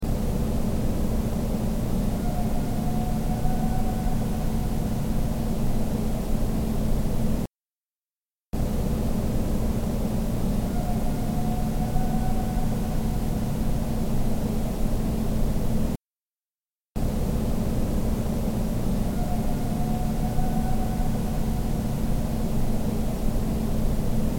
Category: Animals/Nature   Right: Personal
Tags: Wildlife audio recordings Unknow Wildlife Souns